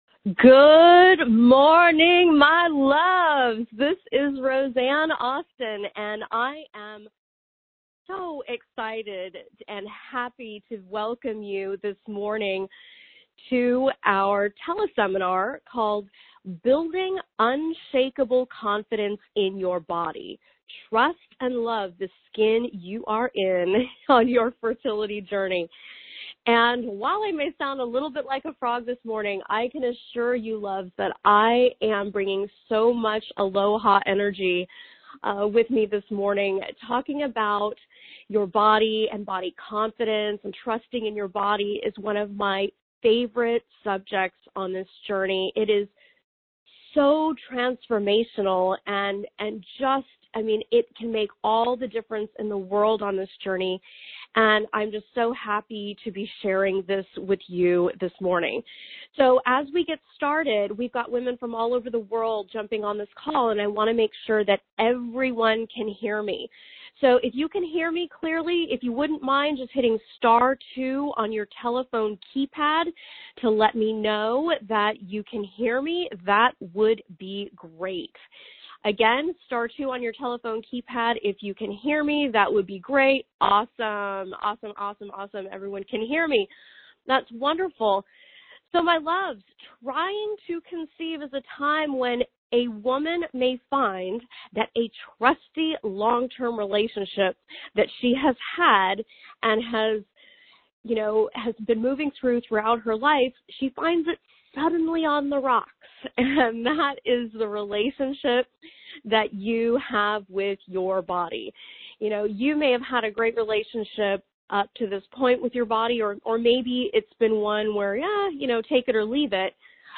Join us for a special conversation about how you can fall in love with your body in spite of the challenges you may encounter on your fertility journey.